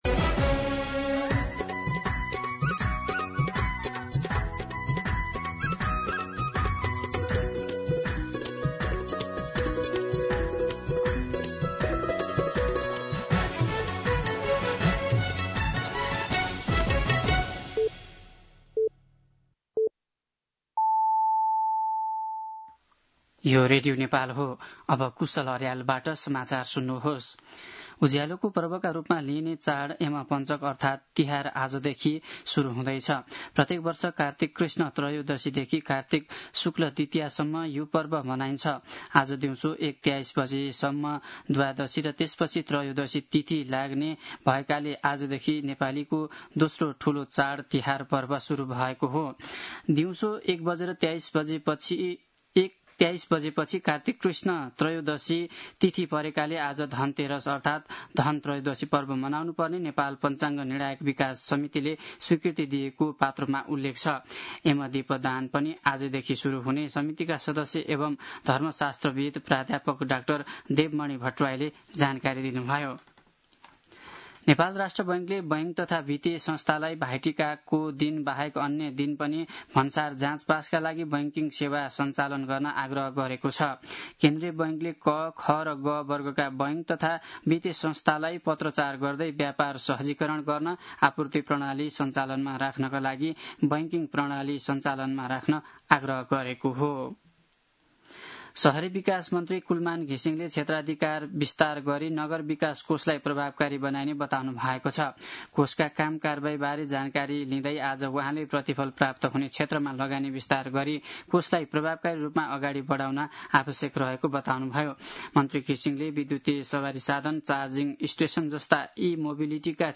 मध्यान्ह १२ बजेको नेपाली समाचार : १ कार्तिक , २०८२
12-pm-Nepaki-News.mp3